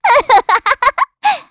Estelle-Vox_Happy3_kr.wav